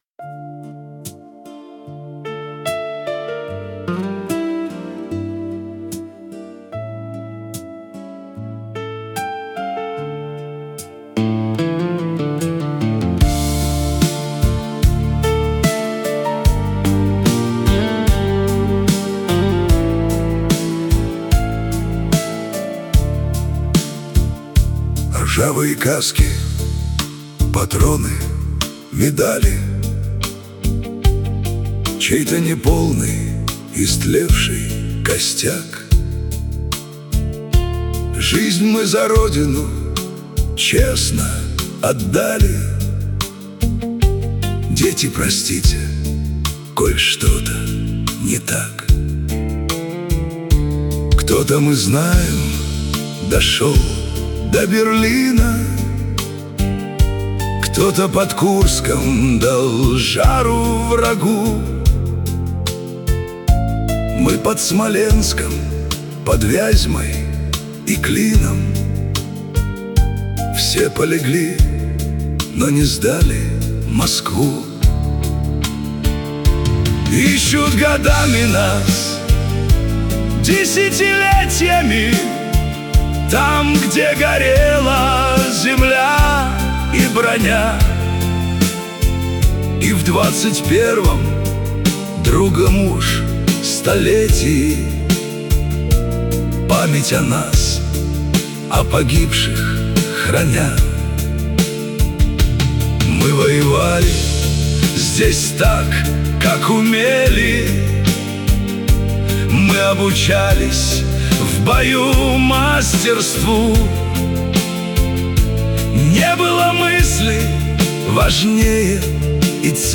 • Аранжировка: Ai
• Жанр: Военная